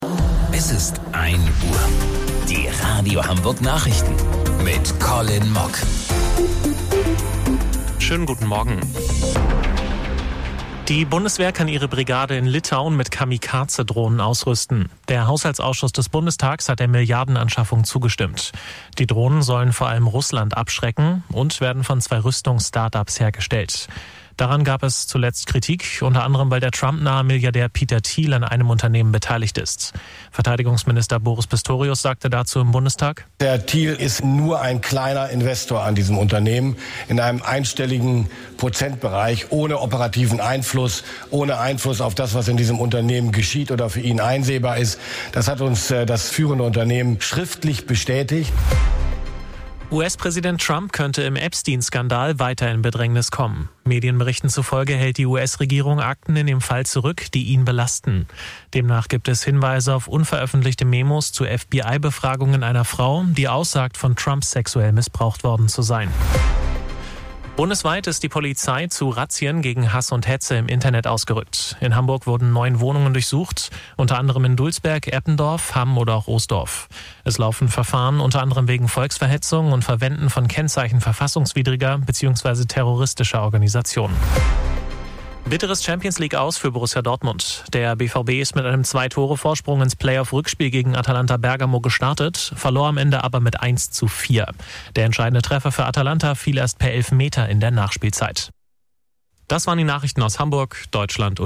Radio Hamburg Nachrichten vom 27.02.2026 um 01 Uhr